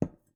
Звуки стеклянной бутылки
Звук бутылки поставленной на стол